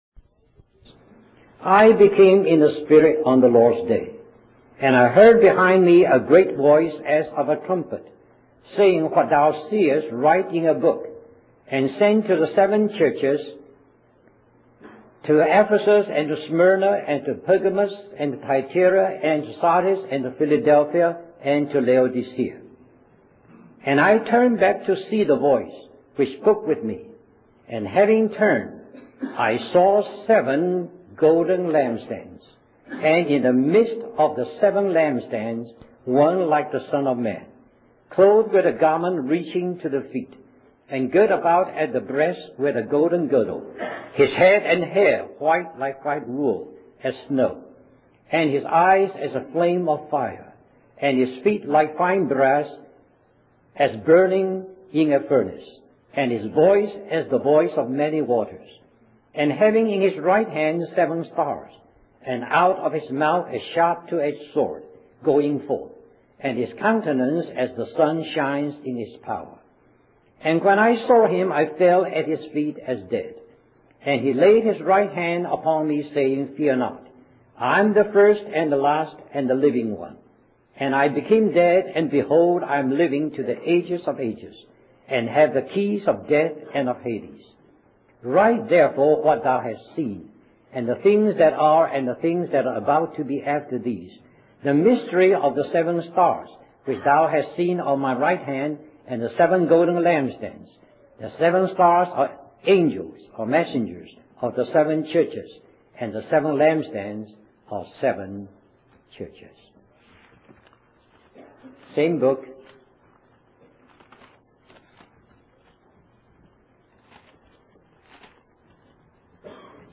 Conference at Bible Institute of Los Angeles